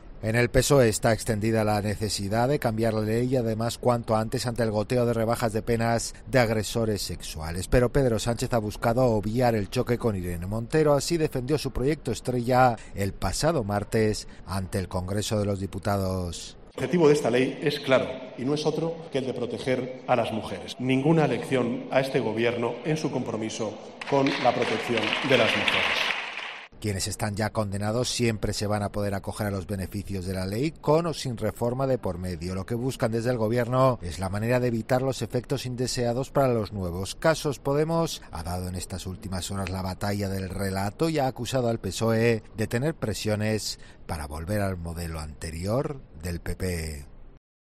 Crónica